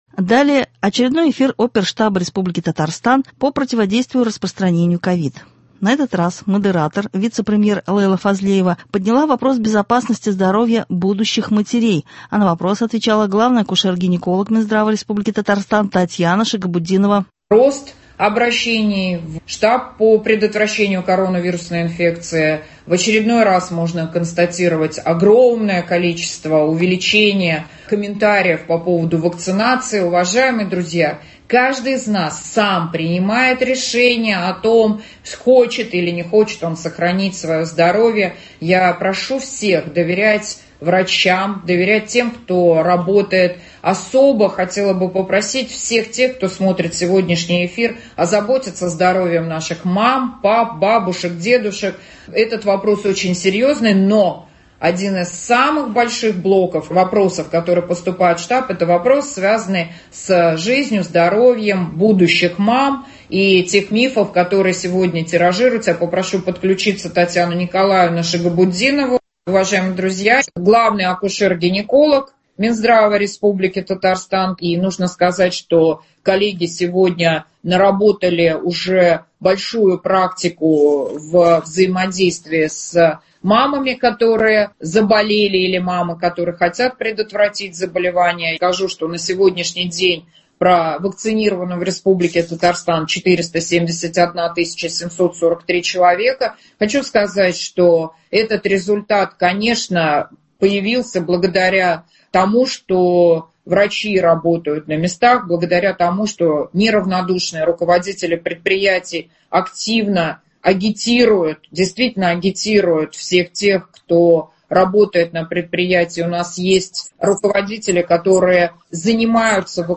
Очередной эфир оперштаба РТ по противодействию КОВИД.